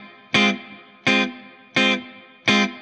DD_StratChop_85-Fmin.wav